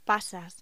Locución: Pasas
voz